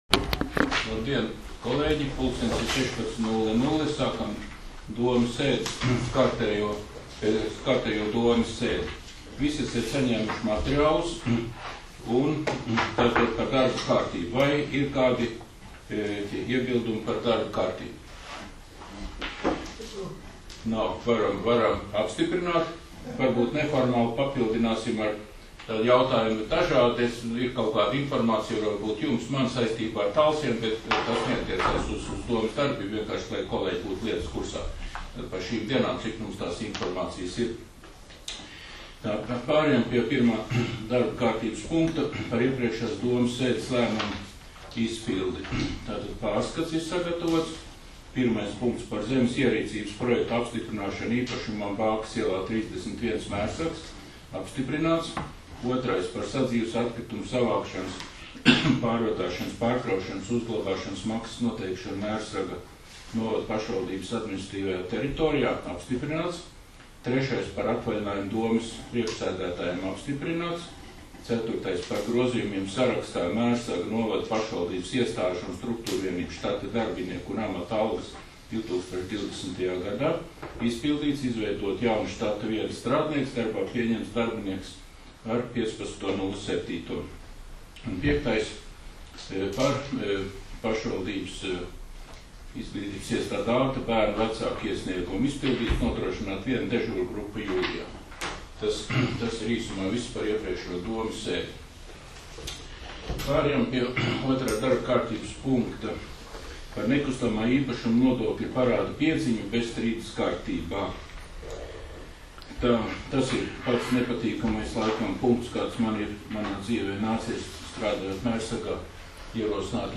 Mērsraga novada domes sēde 21.07.2020.